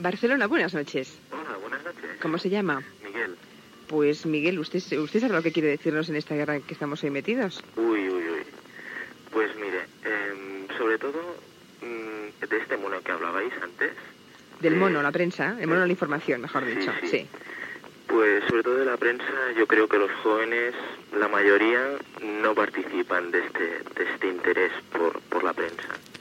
Trucada telefònica d'un oïdor sobre els joves i la premsa
Entreteniment